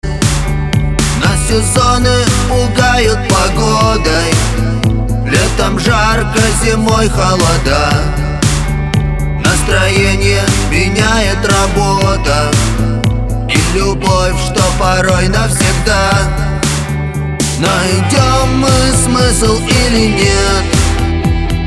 Имеем 2 дорожки вокала, и я вижу это примерно так: 1 дорожка основная: эквалайзер, компрессор, диэсер, не много стереорасширителя например iZotope Vocal Double, чуток ревера . 2 дорожка дабл: эквалайзером срезаем все до 600 гц, компрессор, диээсер, сильное стереорасширение с задержкой 1/8 используем например H delay stereo от Wave/ Правильно я рассуждаю?